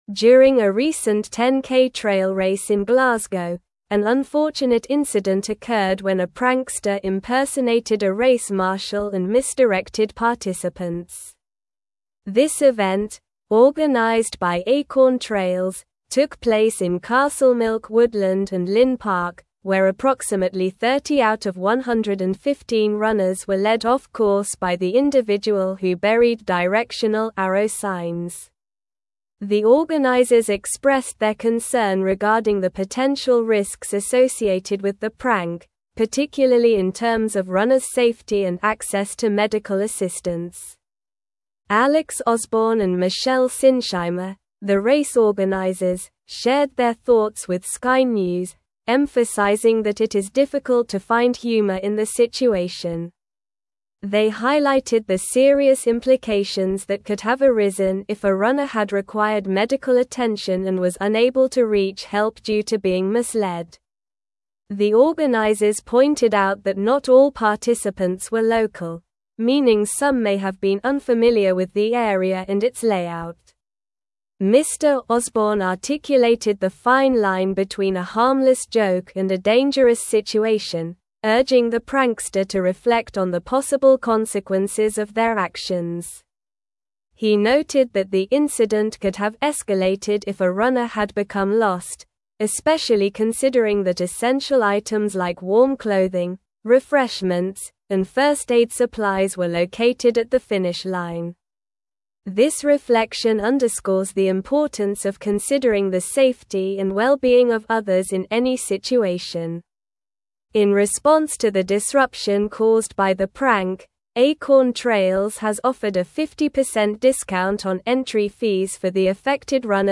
Slow
English-Newsroom-Advanced-SLOW-Reading-Prankster-Disrupts-Glasgow-10k-Trail-Race-Direction.mp3